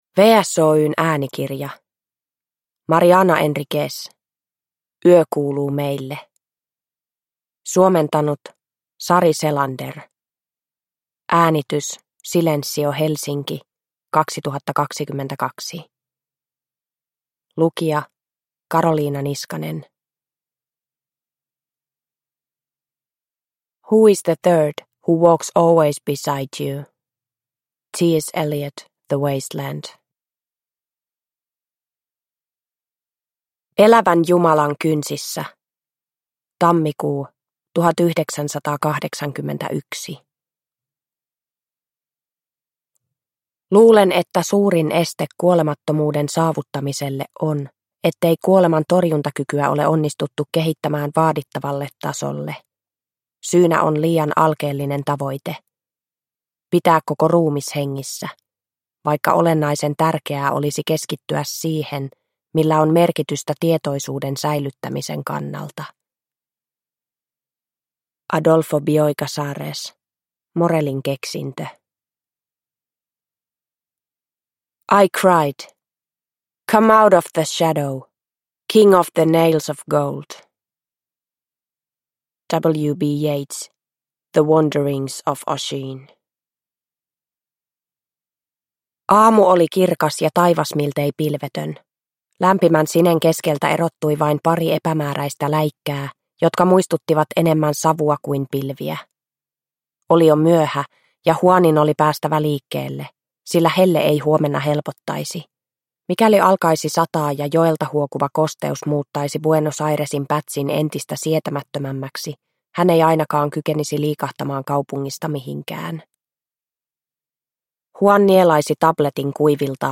Yö kuuluu meille – Ljudbok